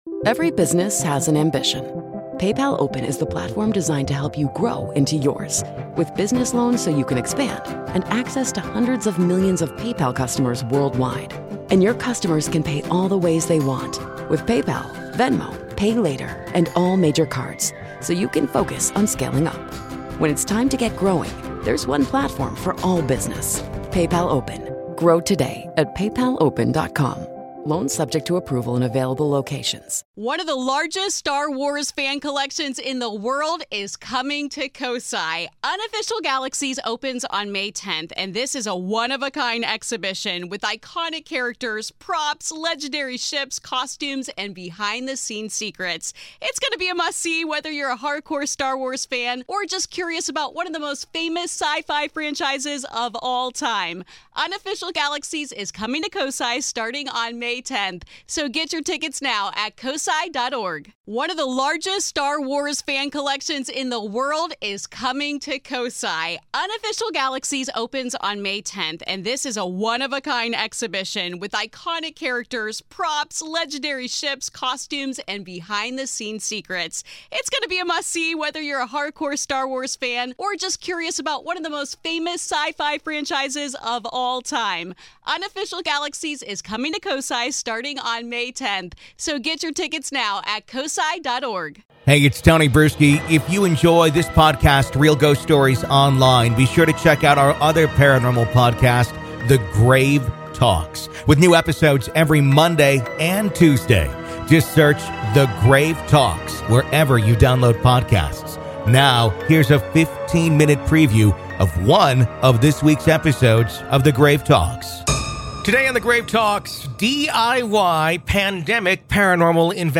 DIY Pandemic Paranormal Investigation A Conversation